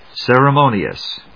音節cer・e・mo・ni・ous 発音記号・読み方
/sèrəmóʊniəs(米国英語)/